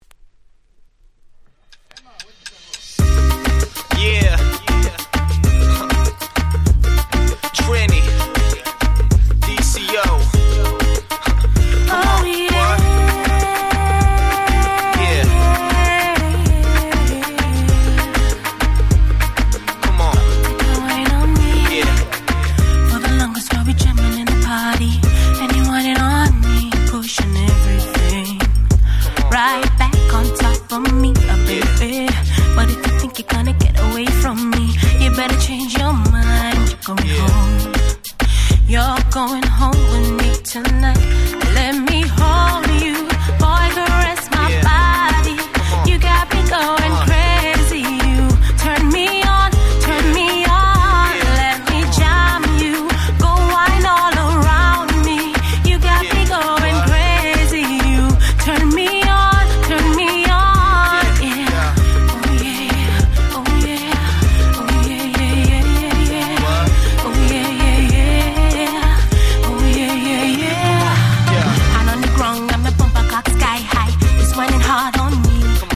の女性カバー！！